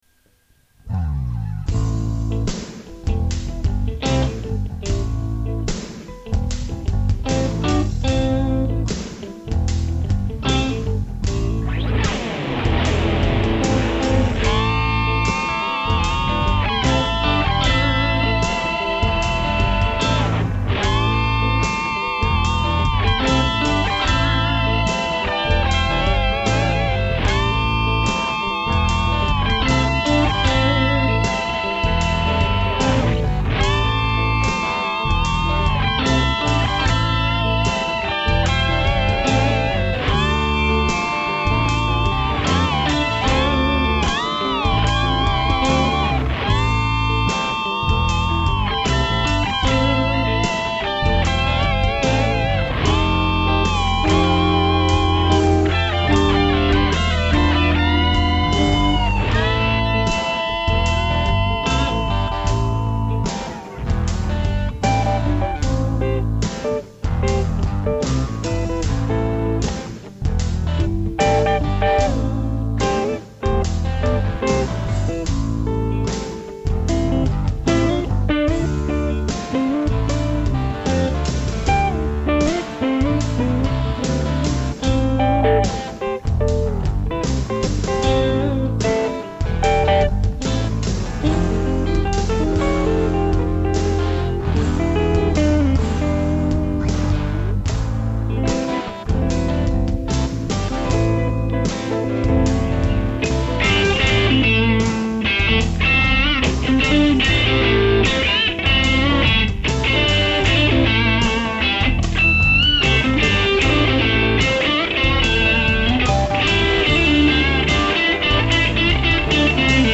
Moin liebe Aussensaiter, Nr. 9 ist abgearbeitet :-) Diesmal spielen drei Gitarristen bei dem Stück mit und jeder darf mal kurz solieren...